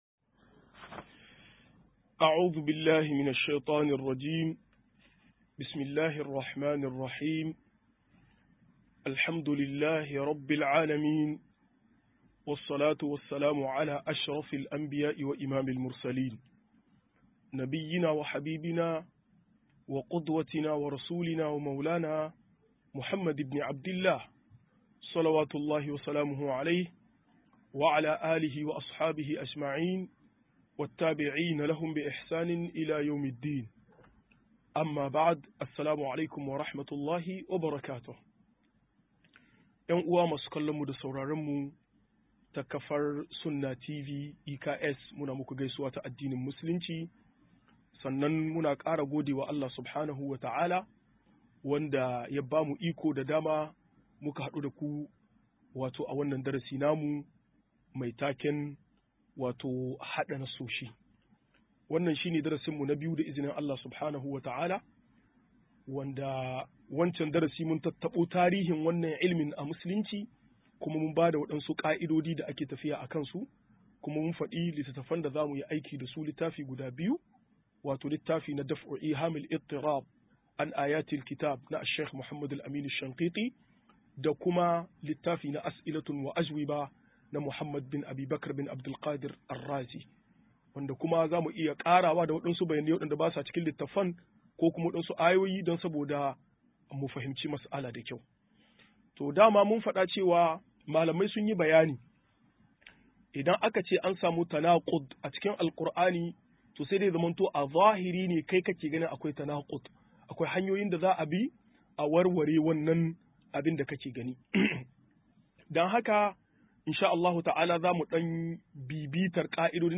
95-Hada Nassishi 2 - MUHADARA